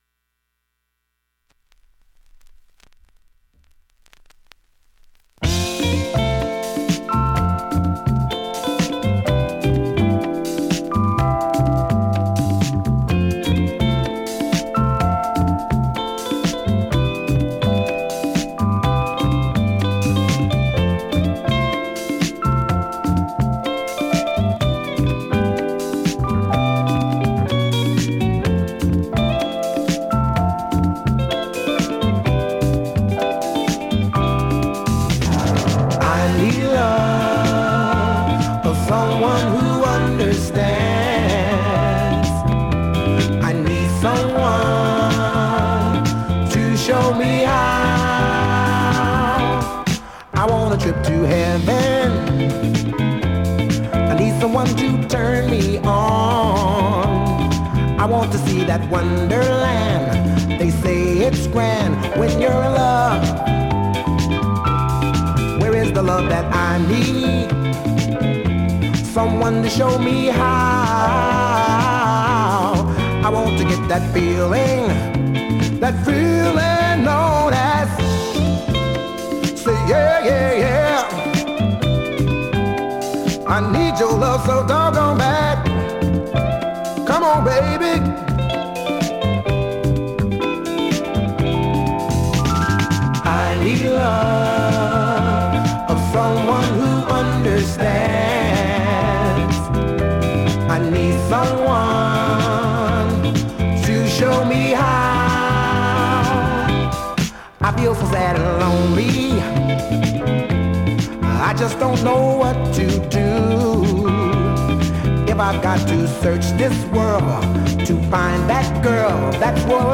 ◆UK盤 7"Single 45 RPM
現物の試聴（両面すべて録音時間6分3秒）できます。